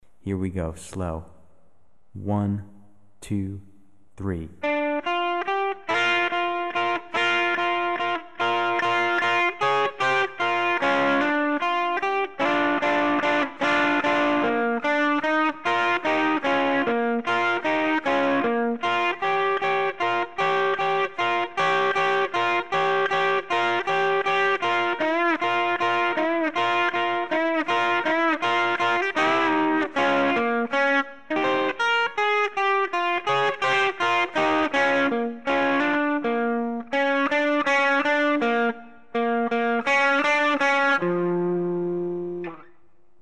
В следующем отрывке представленное в нотах соло играется медленно.
Соло медленно